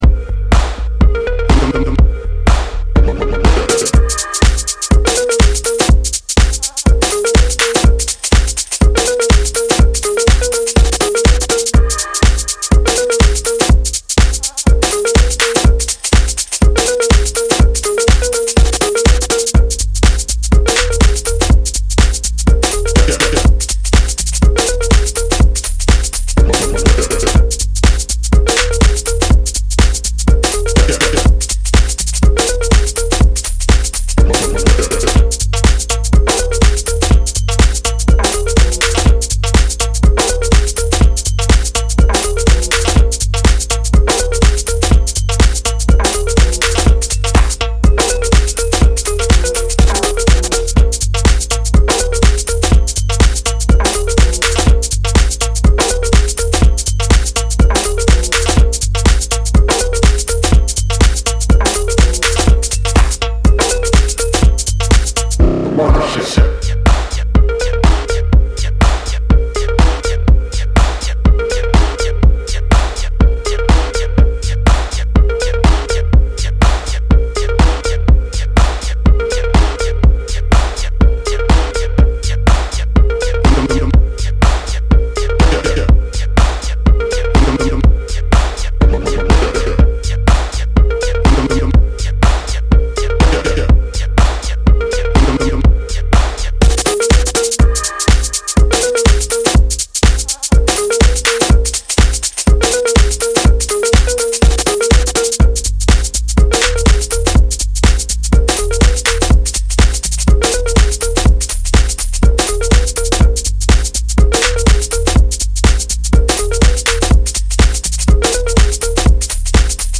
is a deeper, more twisted version